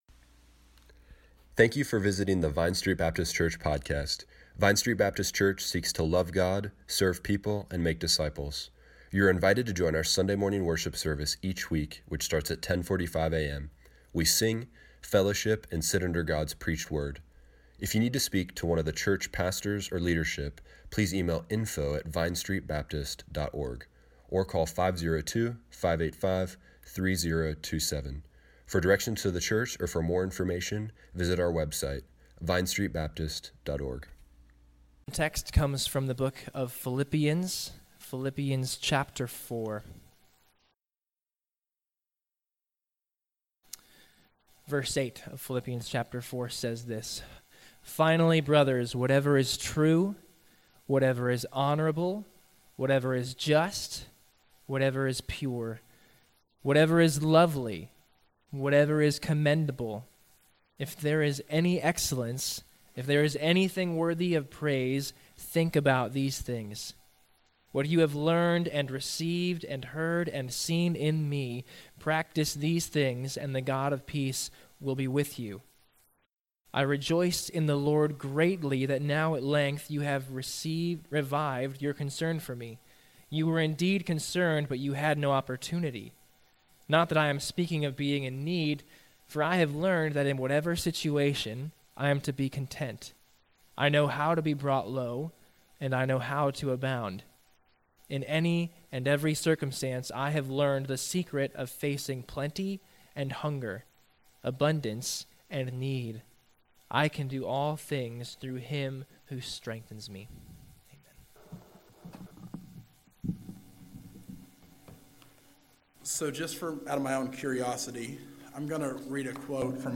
Application: Be content where God has us. Click here to listen to the sermon online.